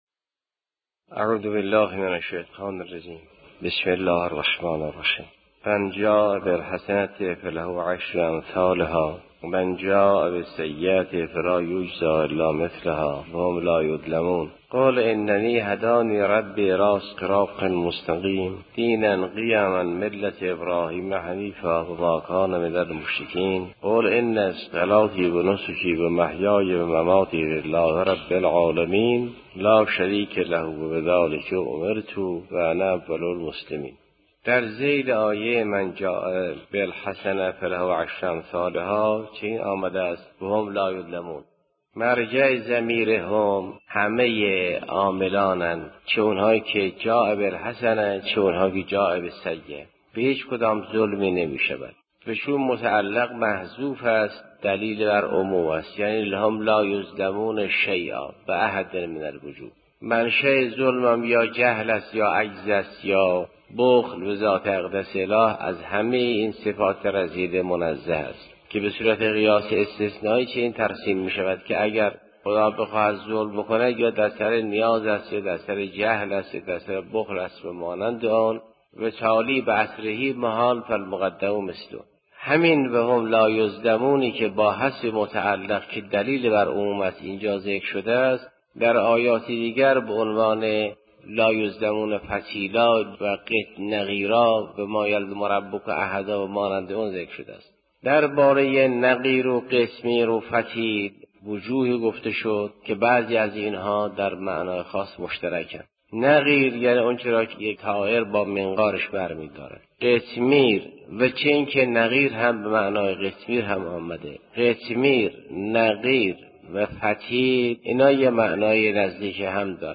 تفسیر سوره انعام جلسه 158